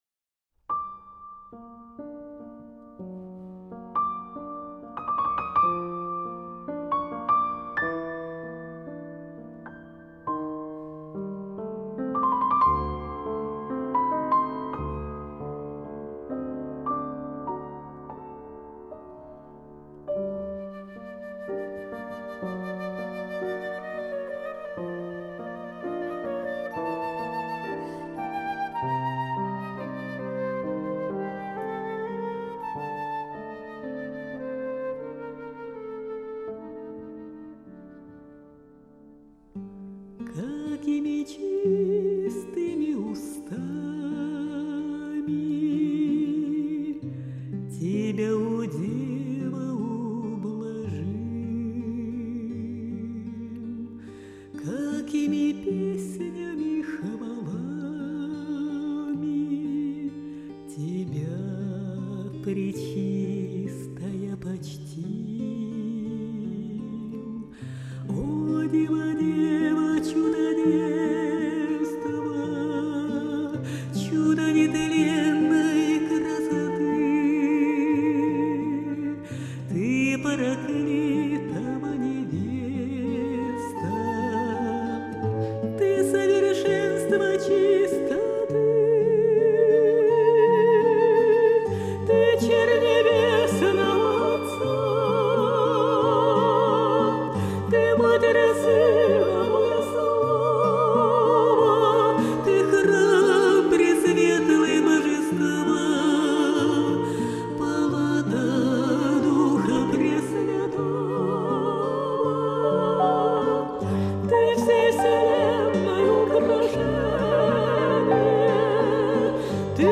Духовная музыка
Она обладает глубоким лирико - драматическим меццо-сопрано.